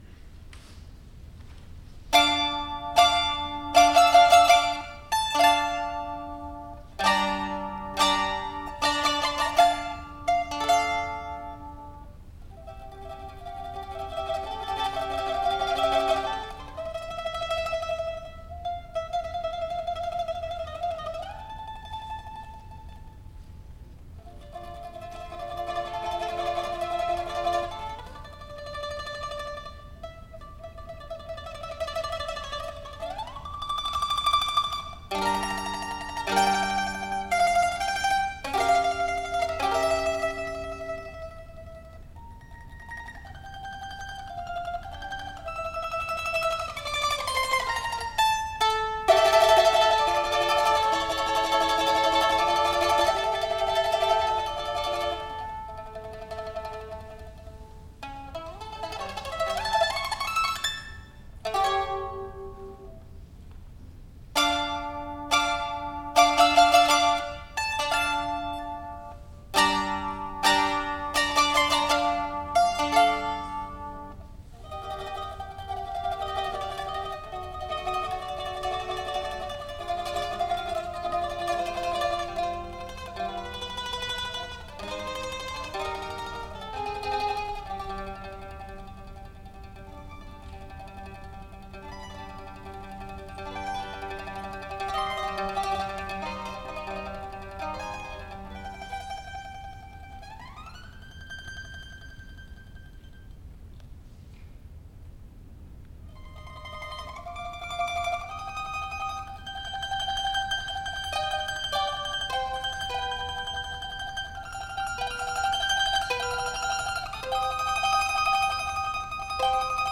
ソロとアンサンブルの夕べ(1980.7.2 府立文化芸術会館)
マンドリン独奏